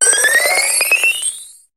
Cri de Prismillon dans Pokémon HOME.